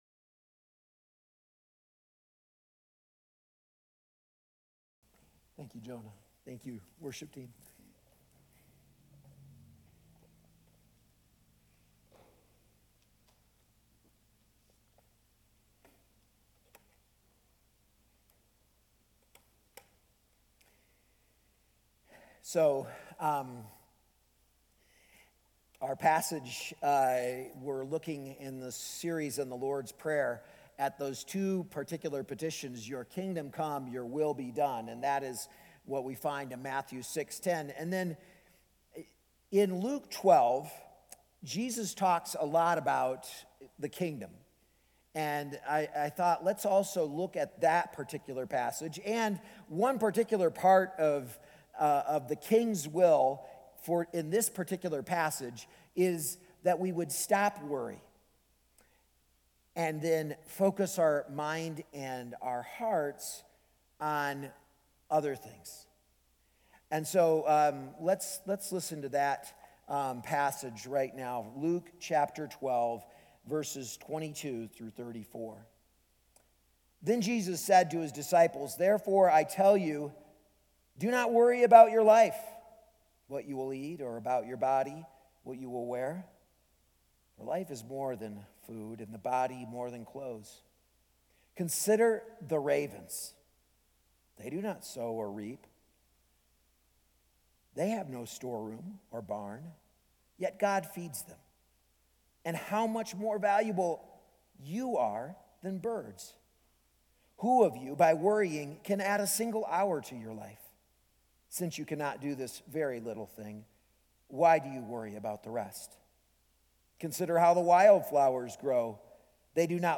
A message from the series "The Lord's Prayer."